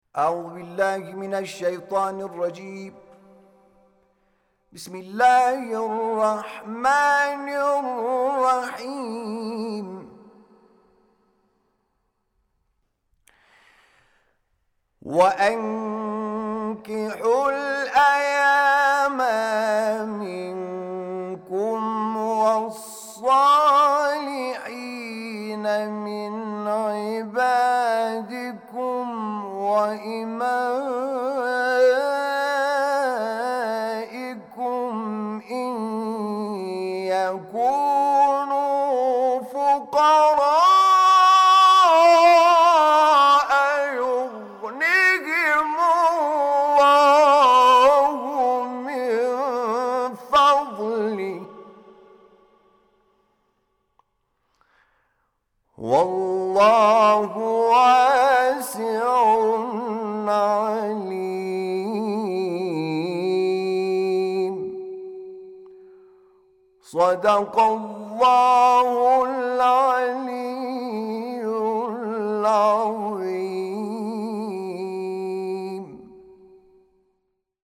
تلاوت آیه ۳۲ سوره مبارکه‌ نور توسط حامد شاکرنژاد